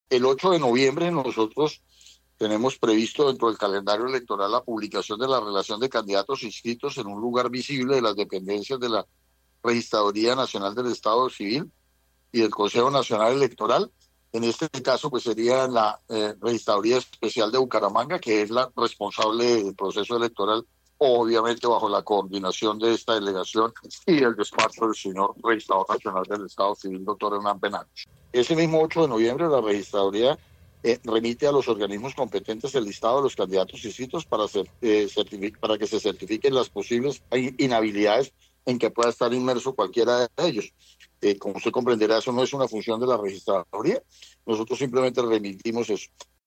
Jose Luis Arias, delegado Registraduría Santander